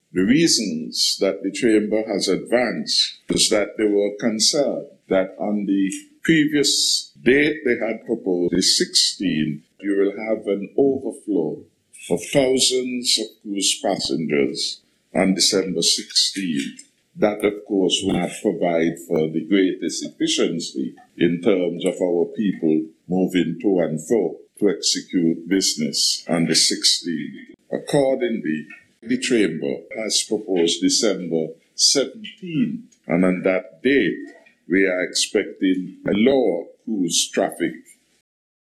The PM made the announcement at a Sitting of the National Assembly, today 1st Dec. this comes following advice from the Chamber of Industry & Commerce.
Prime Minister, Dr. Timothy Harris.